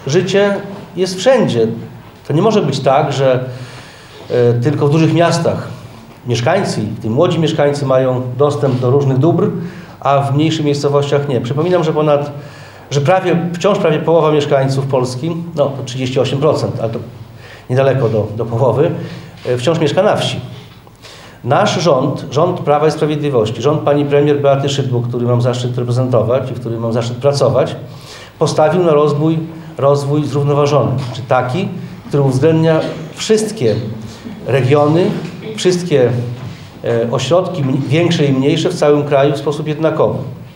Efekt jest taki, że samorząd otrzymał pomoc i prawdopodobnie w przyszłym roku budynek przejdzie modernizację i siłownia będzie gotowa. Szczegóły przedstawiła Dorota Winiewicz, wójt gminy Nowinka